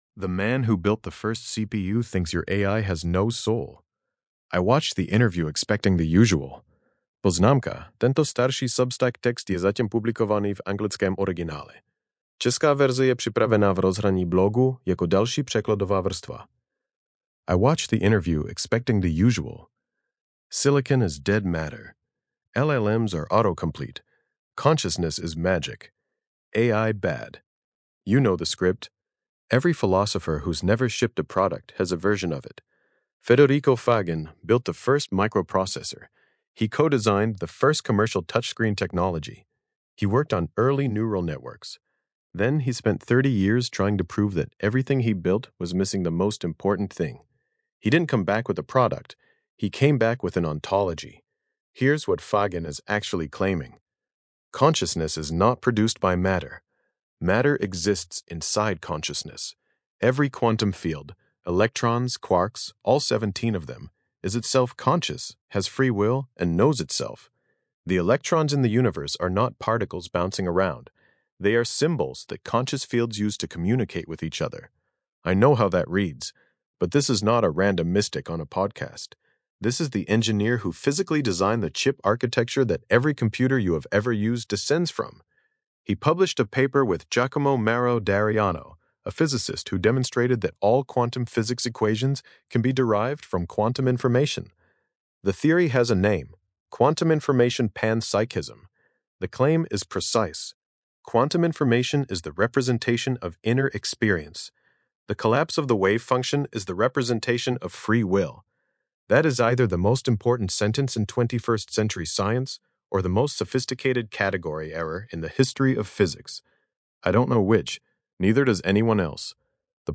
Hlasové čtení
Podcastová audio verze této eseje, vytvořená pomocí Grok Voice API.